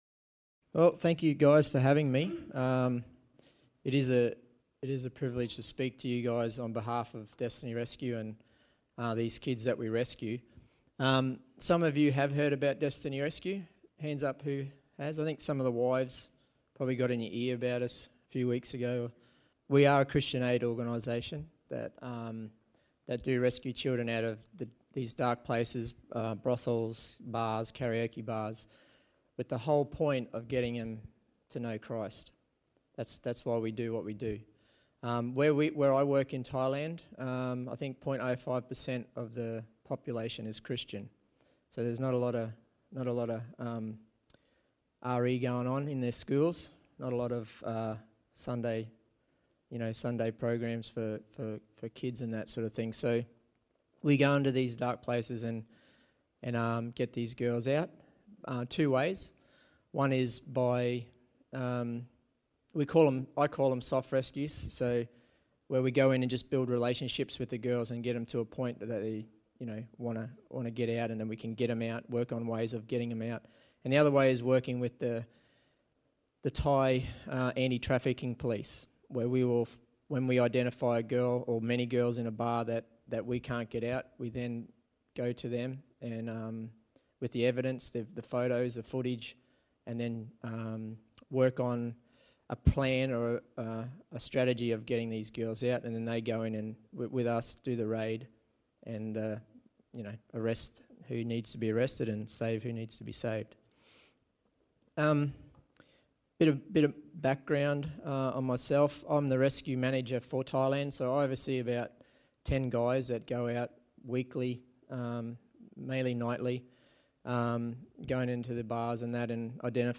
Sermons | St Alfred's Anglican Church
Destiny Rescue is an internationally recognised Christian non-profit organisation dedicated to rescuing children enslaved in the sex trafficking industry. In this sermon, Destiny Rescue speaks on the theme of 'Destiny Rescue' as part of the series 'Men's Breakfast'.